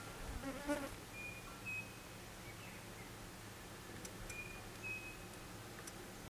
Saci (Tapera naevia)
Nome em Inglês: Striped Cuckoo
Fase da vida: Adulto
Localidade ou área protegida: Reserva Natural Privada Ecoportal de Piedra
Condição: Selvagem
Certeza: Gravado Vocal